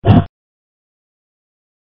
BattleAxe.wav